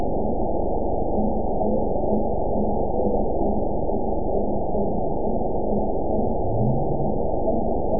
event 922428 date 12/31/24 time 22:27:37 GMT (5 months, 2 weeks ago) score 8.97 location TSS-AB04 detected by nrw target species NRW annotations +NRW Spectrogram: Frequency (kHz) vs. Time (s) audio not available .wav